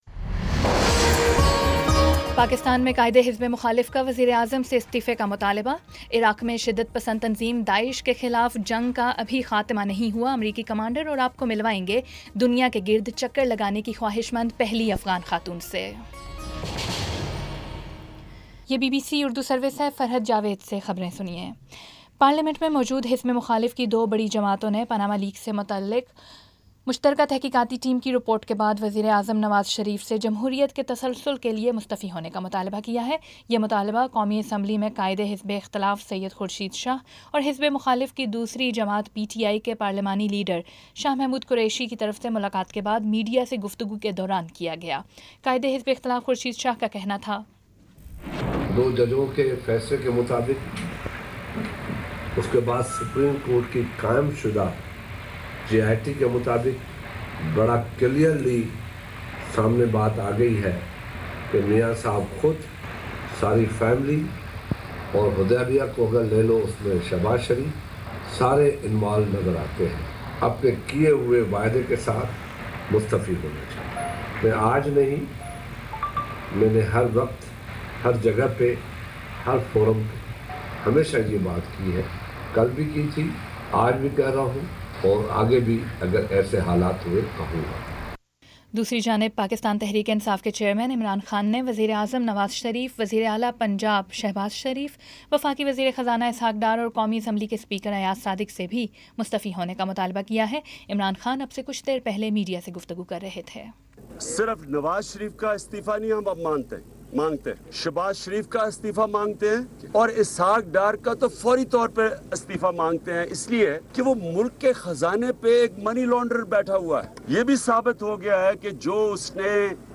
جولائی 11 : شام چھ بجے کا نیوز بُلیٹن